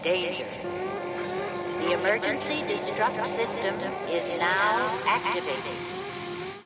The quality is kept to a minimum because of webspace limitations.